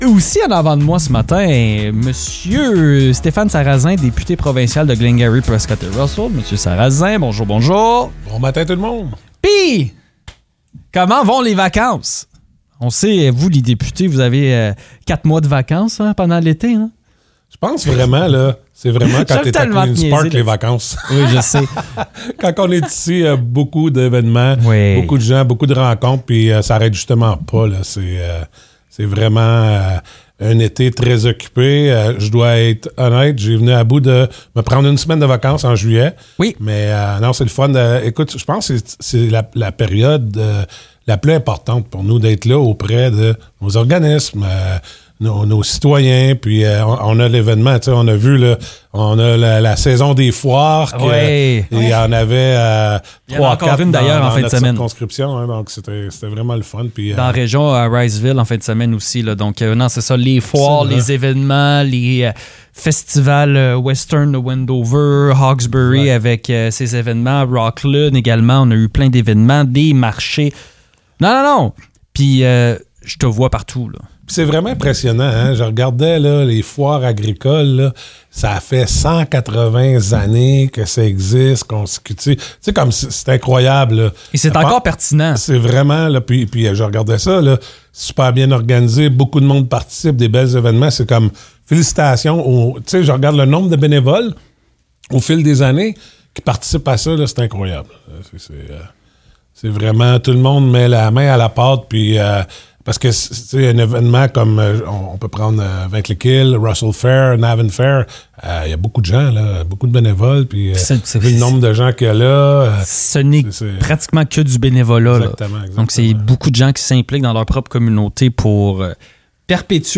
Le député provincial de Glengarry-Prescott-Russell, Stéphane Sarrazin, est venu passer une heure avec nous en studio.